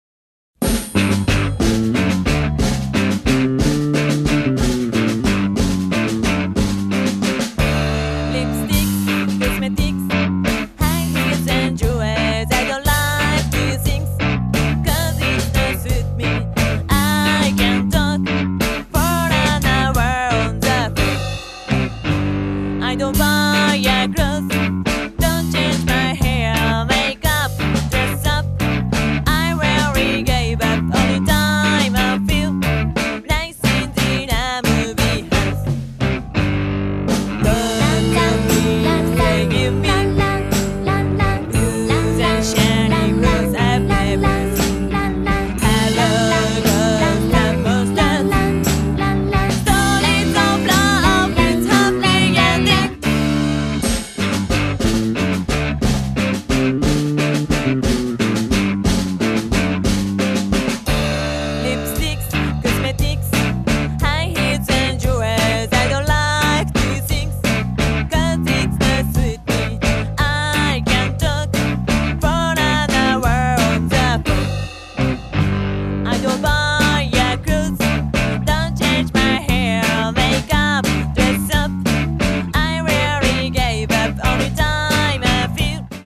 8 all girl bands from China, Korea and Japan !
以外と硬派でストレートなロックンロールアルバムになった
2001年5月から３ピースになりサウンドも一新。